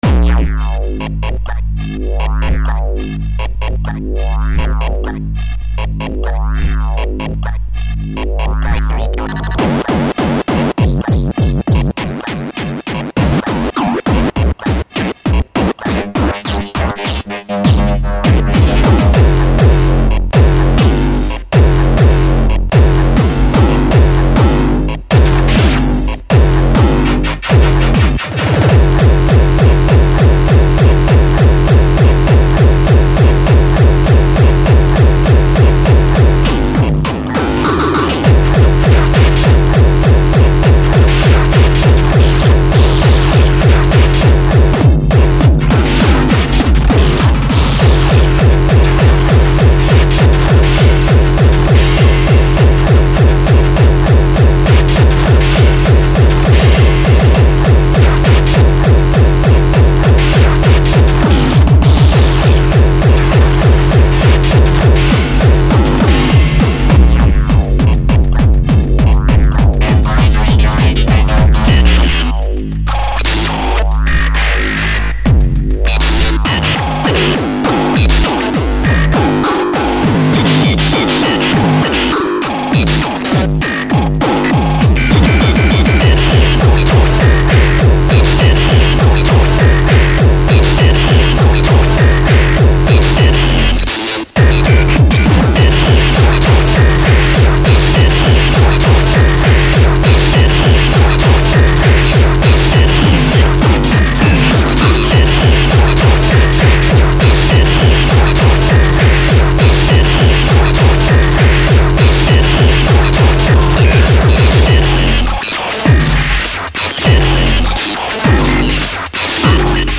200 BPM